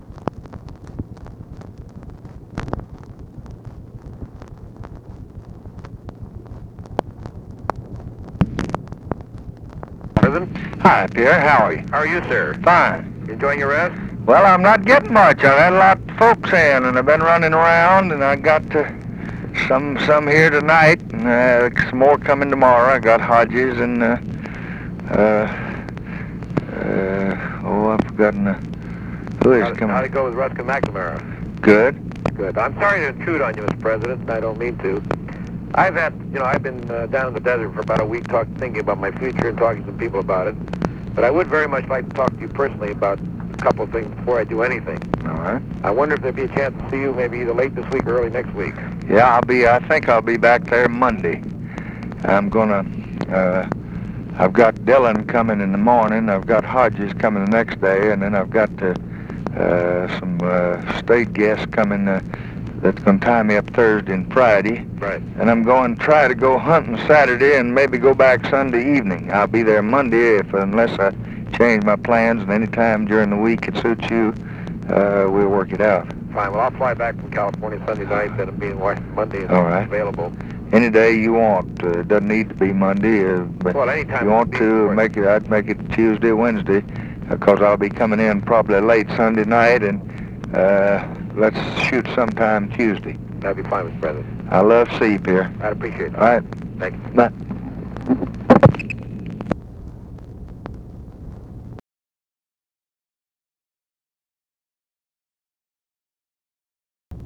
Conversation with PIERRE SALINGER, November 11, 1964
Secret White House Tapes